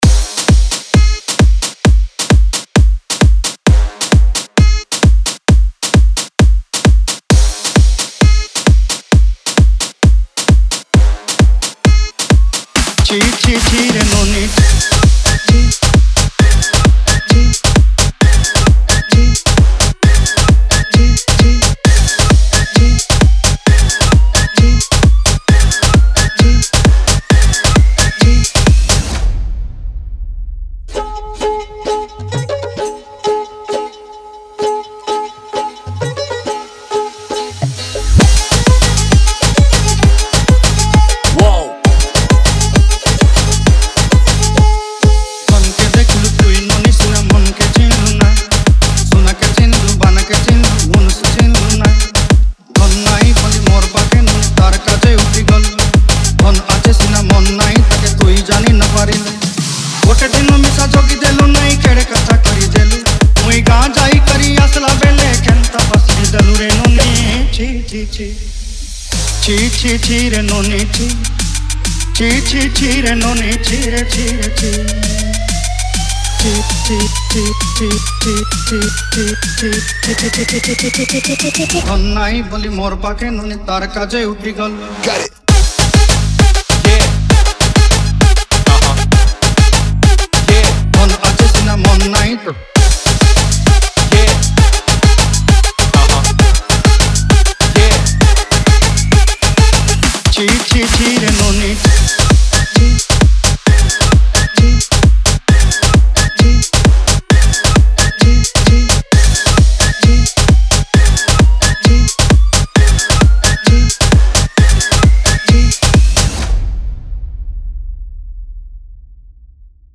Regional Single Remixes